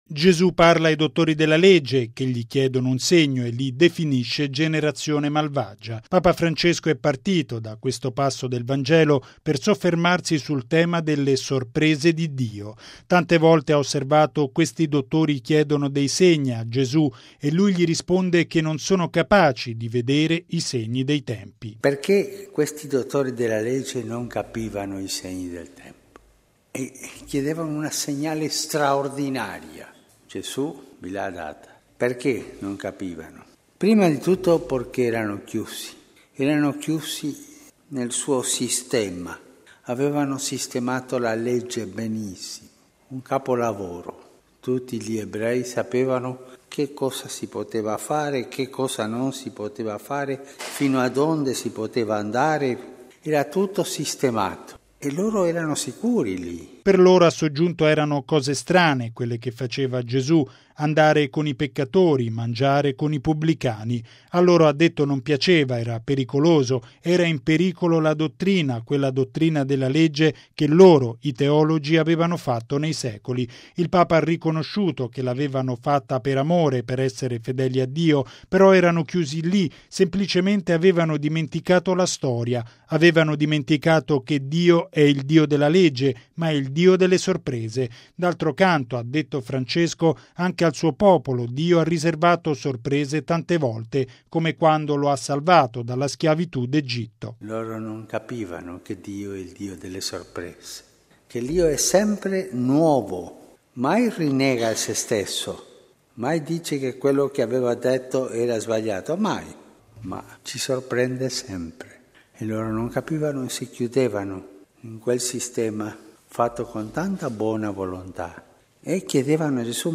E’ quanto affermato da Papa Francesco nella Messa mattutina a Casa Santa Marta. Commentando le parole di Gesù ai dottori della legge , il Papa ha esortato i fedeli a non rimanere attaccati alle proprie idee, ma a camminare con il Signore trovando sempre cose nuove.